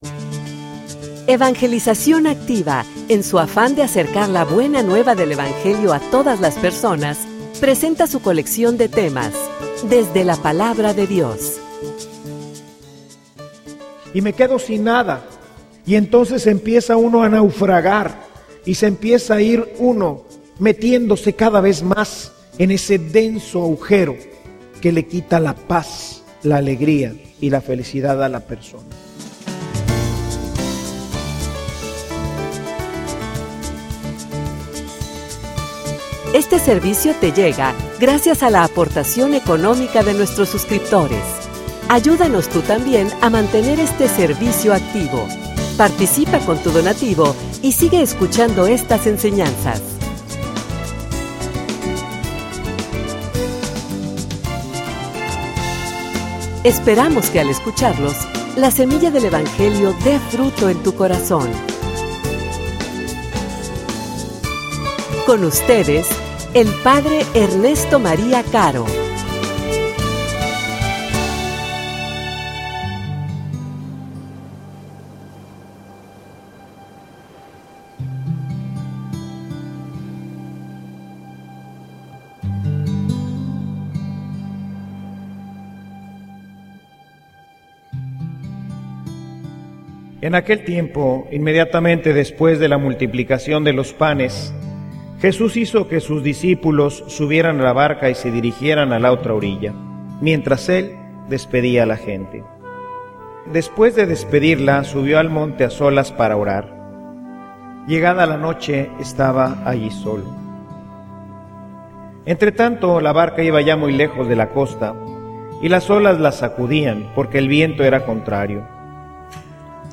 homilia_Confianza_plena.mp3